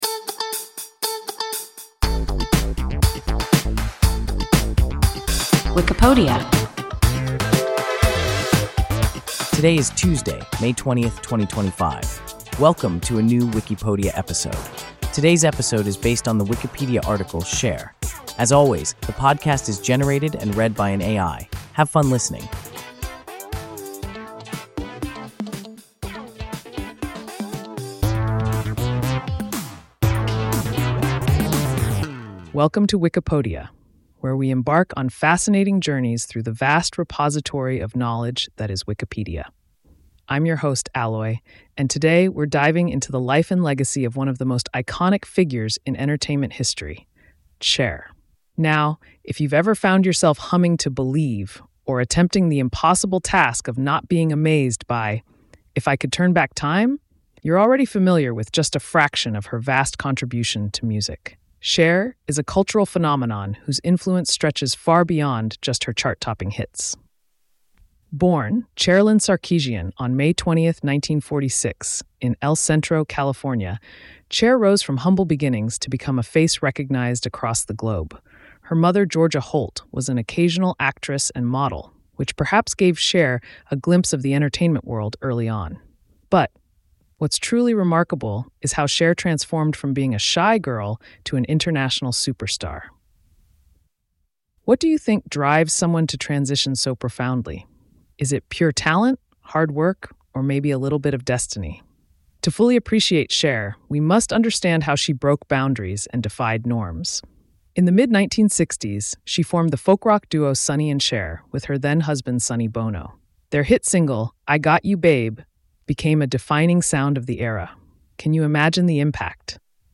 Cher – WIKIPODIA – ein KI Podcast